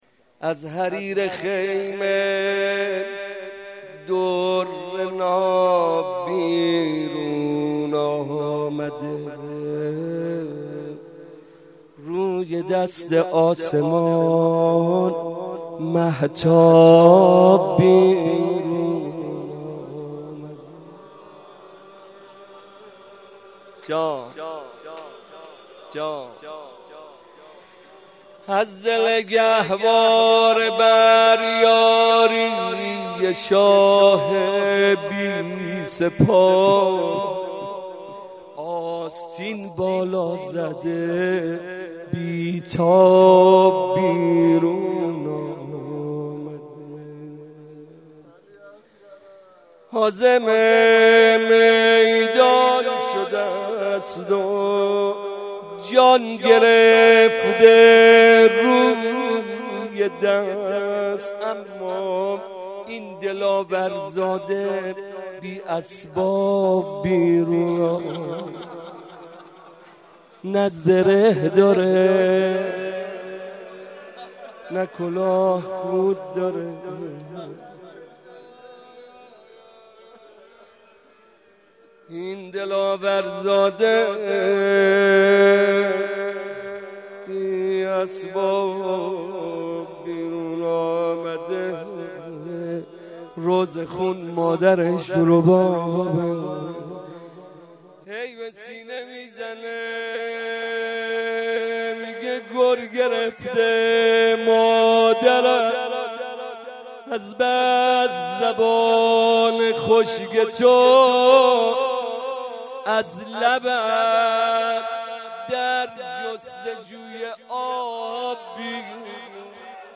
02.rozeh.mp3